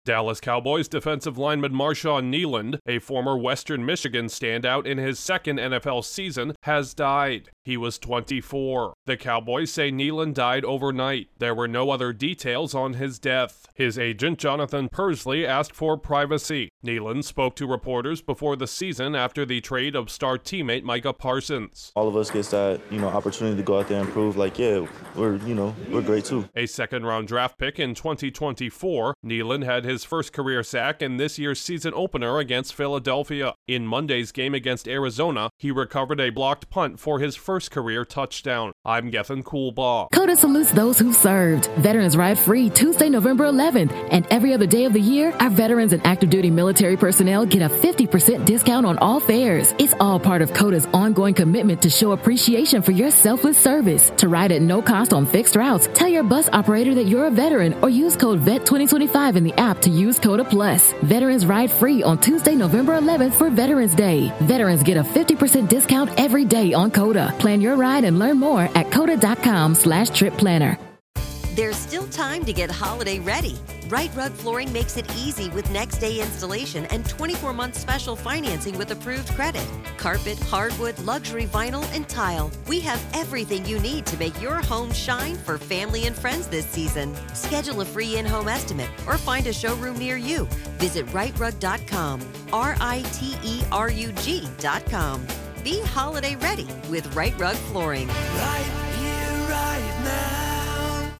A young Dallas Cowboys player was found dead in a suspected suicide after evading police. Correspondent